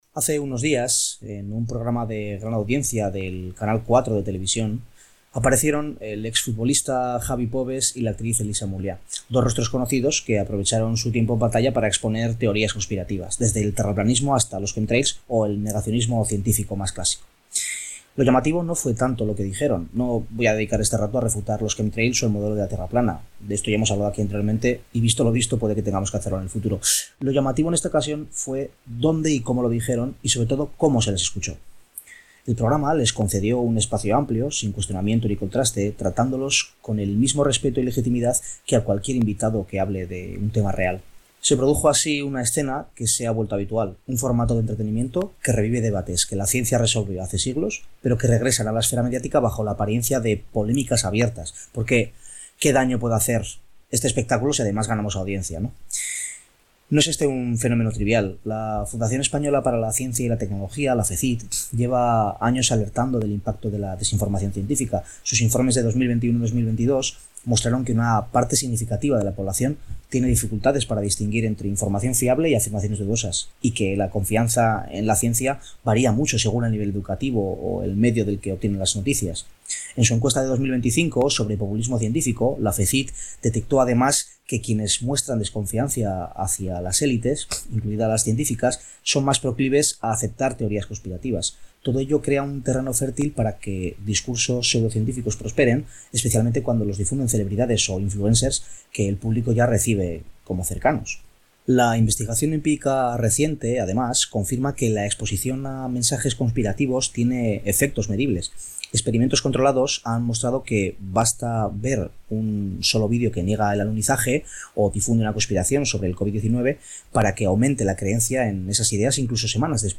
Durante la conversación, se ha mencionado que la Fundación Española para la Ciencia y la Tecnología (FECYT) ha alertado en sus informes recientes de la dificultad de una parte de la población para distinguir entre información fiable y afirmaciones dudosas, así como de cómo la confianza en la ciencia ha dependido del nivel educativo y de las fuentes informativas.